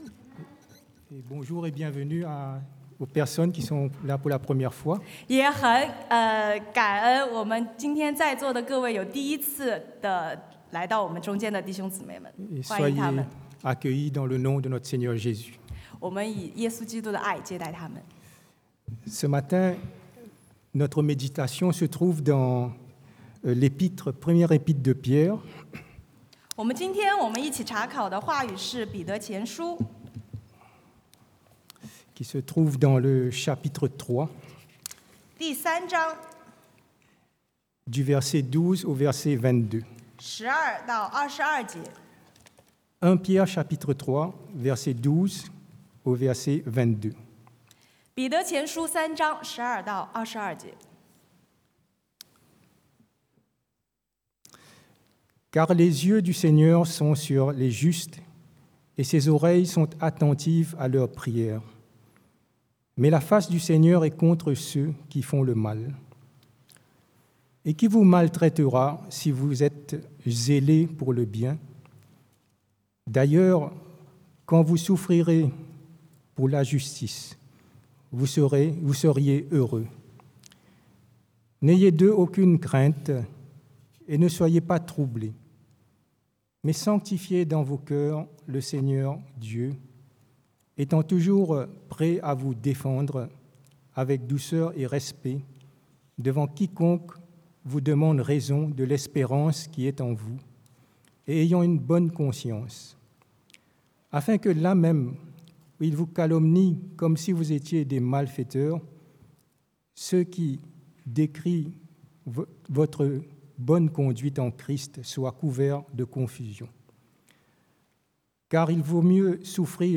Type De Service: Predication du dimanche